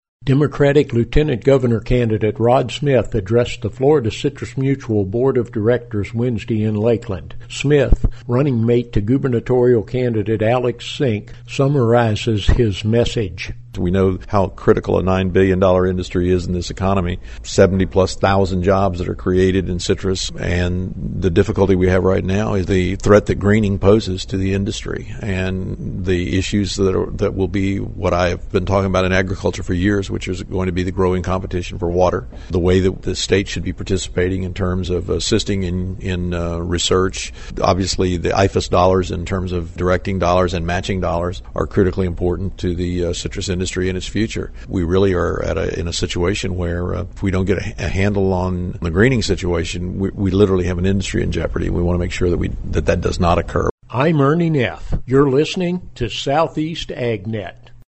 Democratic lieutenant governor candidate Rod Smith summarizes his Wednesday message to the Florida Citrus Mutual Board of Directors.